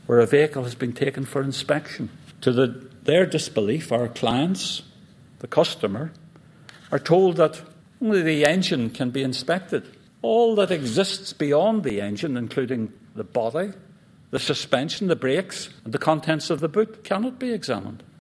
At the inquiry today
read an opening statement from solicitors representing families